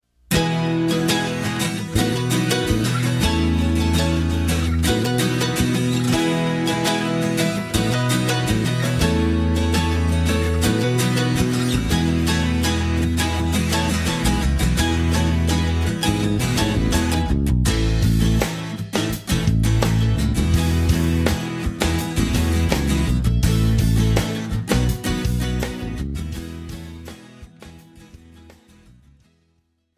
This is an instrumental backing track cover.
• Key – E
• With Backing Vocals
• No Fade